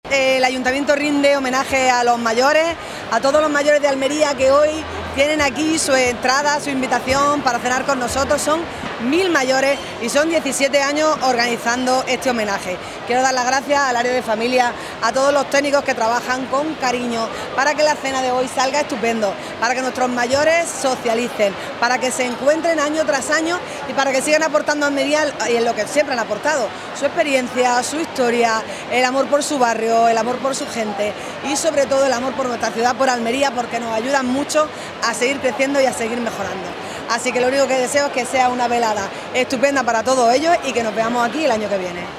La alcaldesa, María del Mar Vázquez, agradeció desde el escenario ese compromiso y generosidad de los mayores con la ciudad y les emplazó para que sigan activos y vuelvan en 2026.
CORTE-ALCALDESA-HOMENAJE-MAYORES-EN-FERIA.mp3